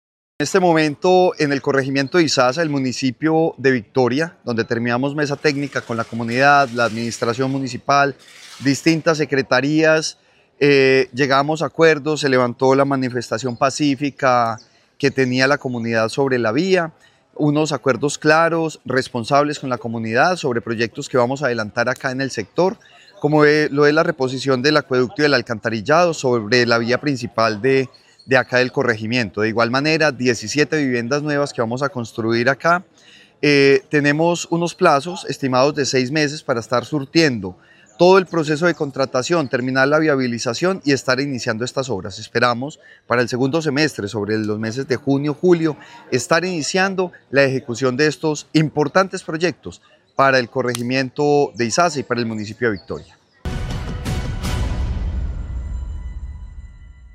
Francisco Vélez Quiroga, secretario de Vivienda.